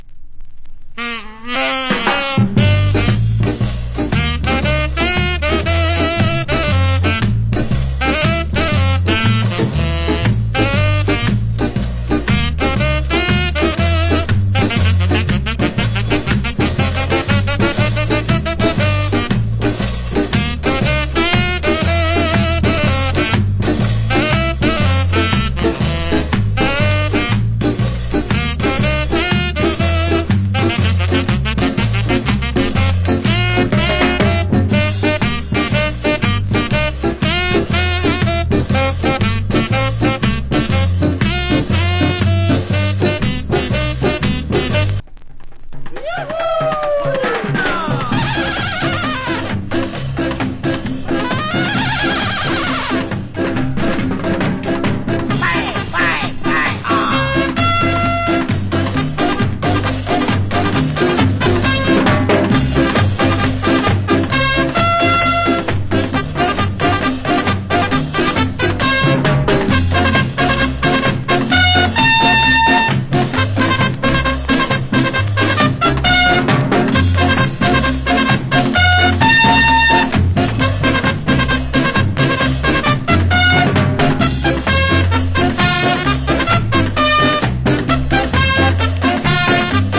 Ska Inst.